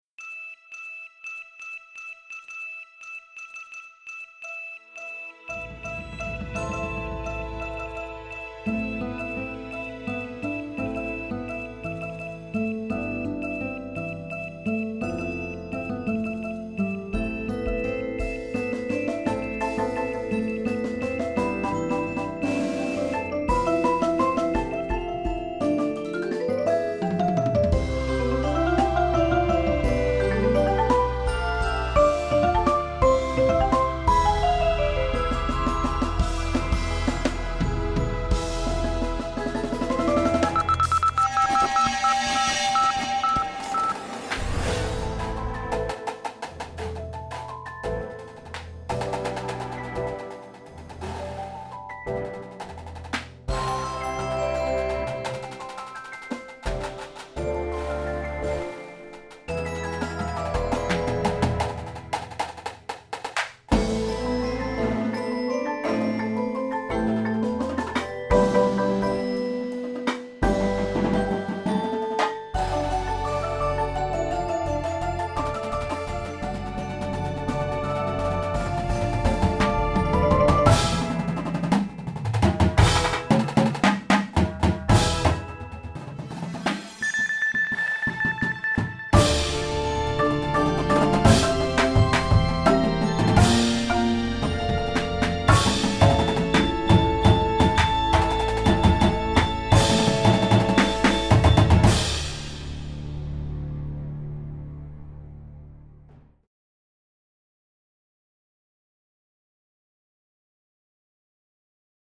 Indoor Percussion Shows
Front Ensemble
• 3-5 Marimbas
• 2 Synths